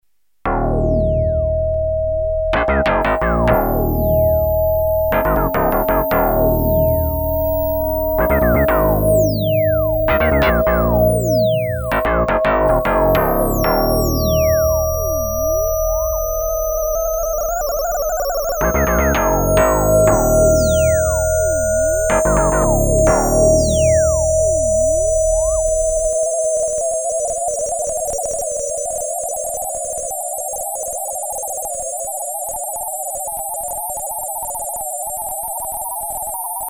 bass + sinewave
Synthesis: FM
bass.mp3